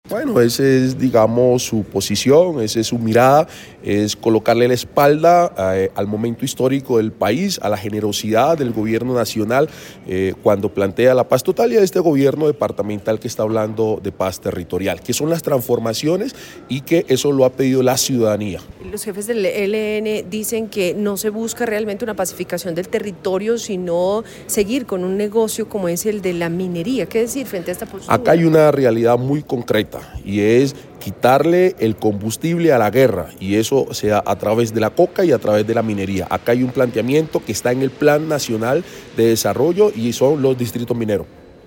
Benildo Estupiñan, secretario de Gobierno de Nariño
En dialogo con Caracol Radio, el secretario de gobierno del departamento de Nariño Benildo Estupiñan respondió a los cuestionamientos del jefe negociador del ELN Pablo Beltrán quien en entrevista con W Radio habló del proceso de paz territorial en Nariño y la propuesta ligada al mismo en relación con la formalización minera y la creación de un Distrito Minero.